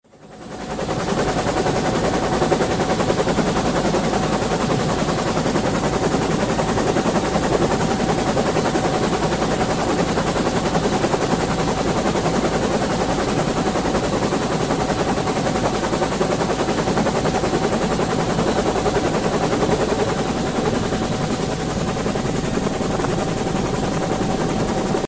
Звуки пропеллера